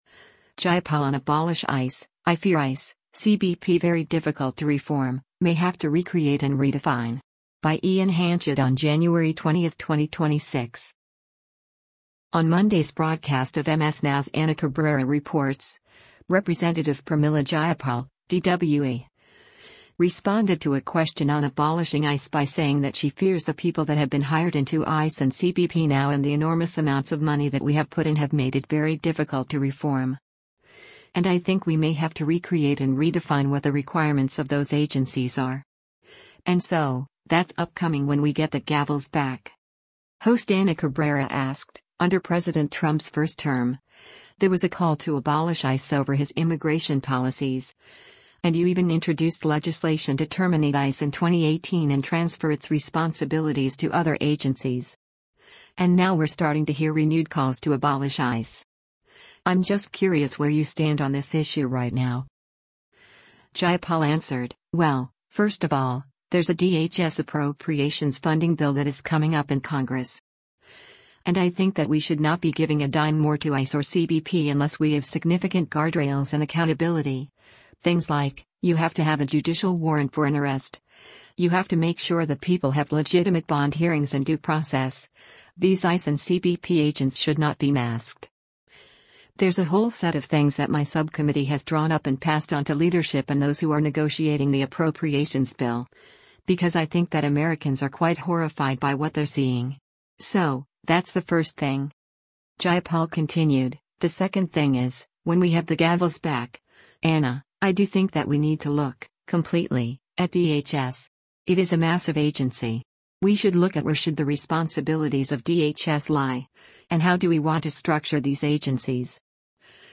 On Monday’s broadcast of MS NOW’s “Ana Cabrera Reports,” Rep. Pramila Jayapal (D-WA) responded to a question on abolishing ICE by saying that she fears “the people that have been hired into ICE and CBP now and the enormous amounts of money that we have put in have made it very difficult to reform. And I think we may have to recreate and redefine what the requirements of those agencies are. And so, that’s upcoming when we get the gavels back.”